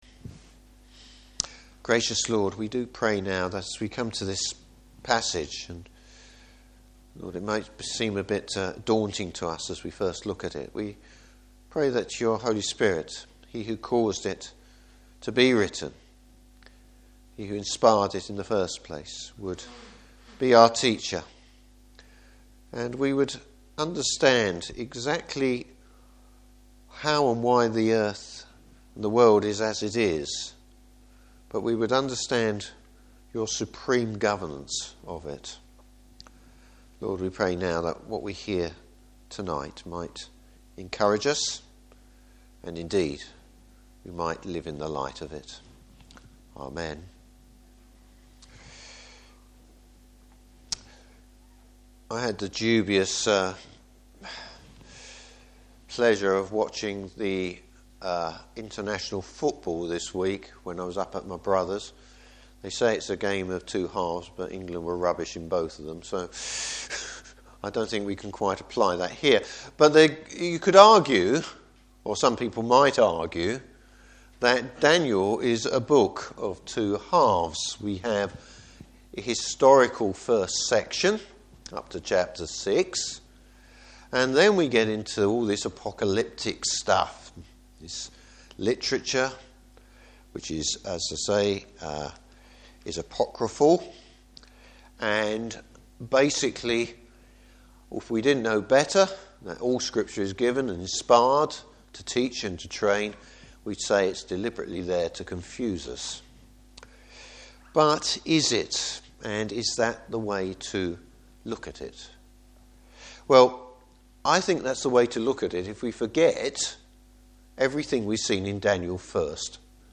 Passage: Daniel 7. Service Type: Evening Service Christ over all!